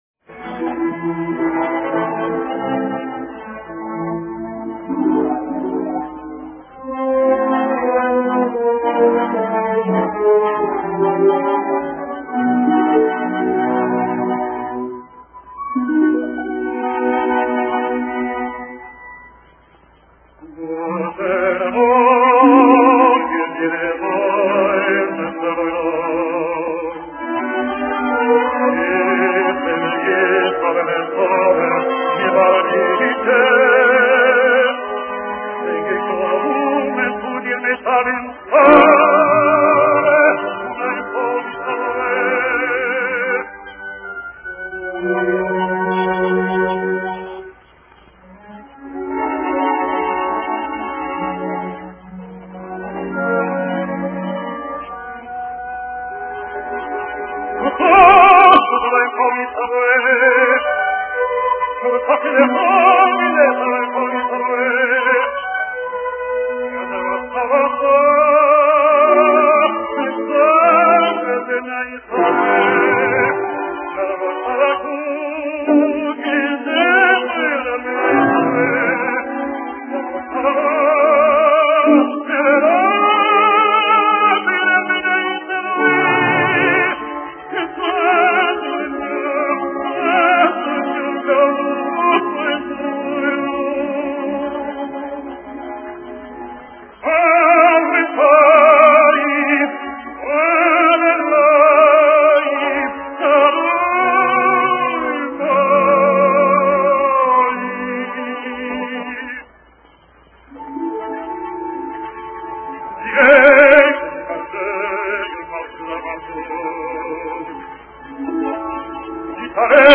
מפי החזן האדיר גרשון סירוטה , שבו מתעמת ר' לוי יצחק מברדיצ'ב – סניגורם של ישראל (אוי, כמה זקוקים אנו כעת לסניגור שכזה) - עם הקב''ה. כן מצורפות המילים ותרגומן לעברית.